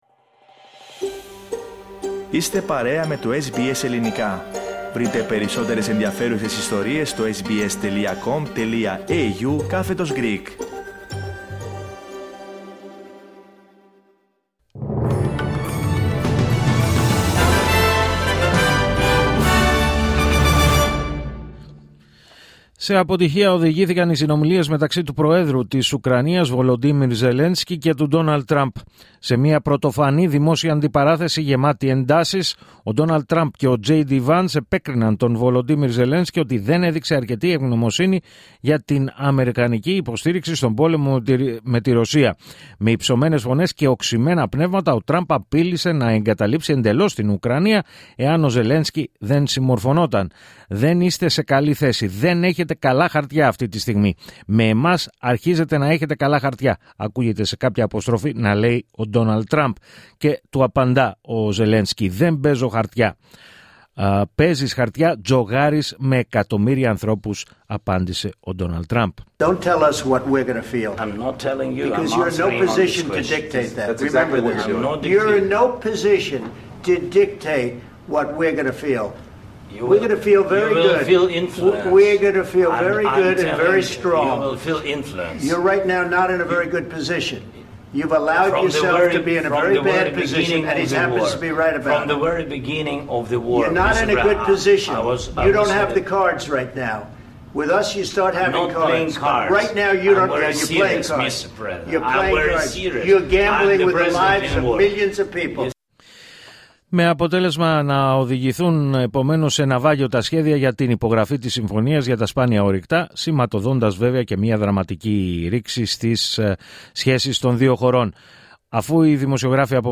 Δελτίο Ειδήσεων Σάββατο 1η Μαρτίου 2025